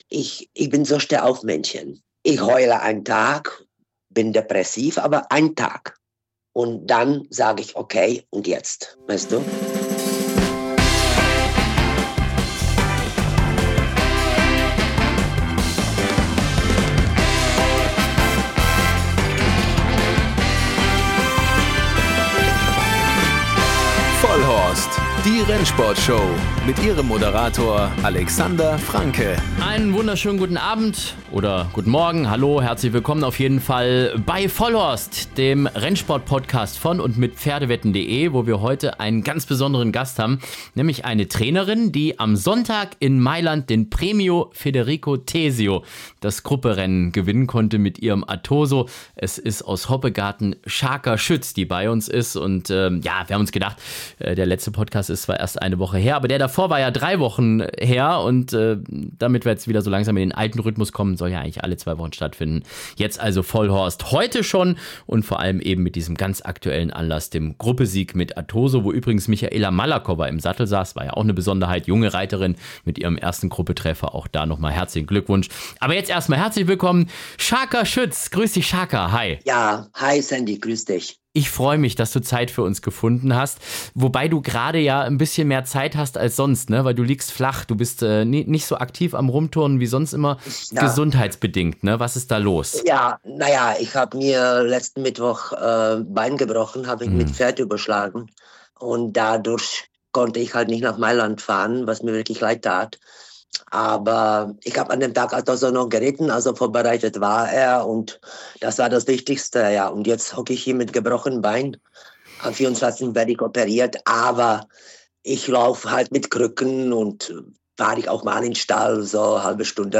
Wir diskutieren leidenschaftlich über Reitstil, den Sinn und Unsinn der Peitsche im Rennsport und was Menschlichkeit im Stall wirklich ausmacht. Hört rein für ein ehrliches, emotionales und inspirierendes Gespräch aus dem Herzen des Galoppsports.